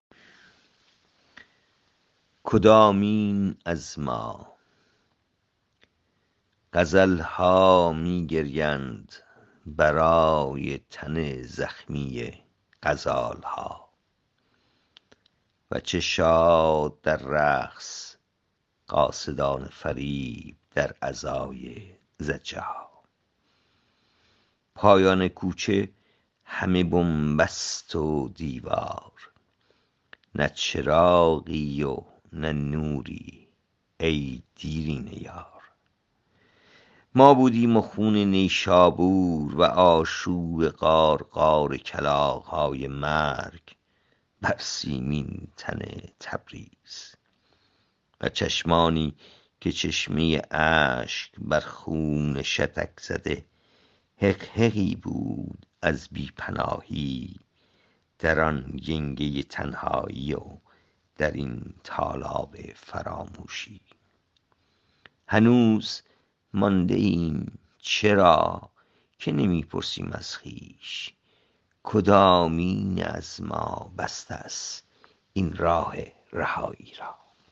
این شعر را با صدای شاعر از این‌جا بشنوید